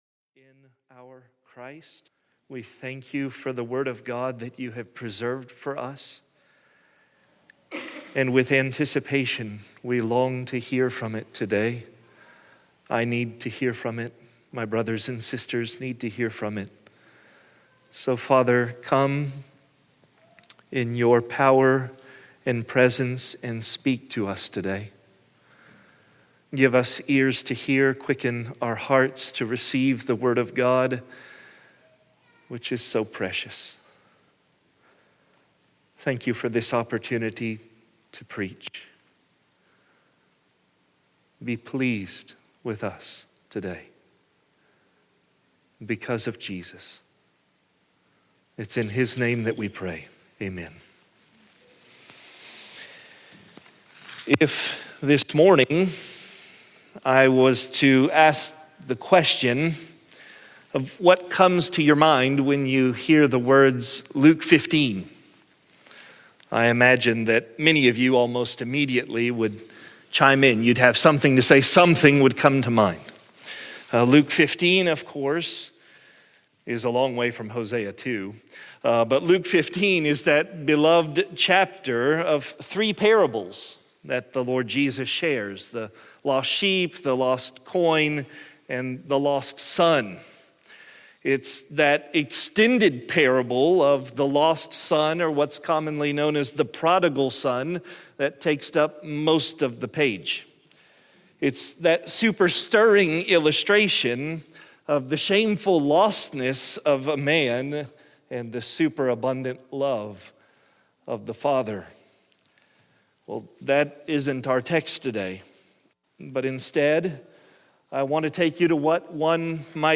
God's Heart for Us | SermonAudio Broadcaster is Live View the Live Stream Share this sermon Disabled by adblocker Copy URL Copied!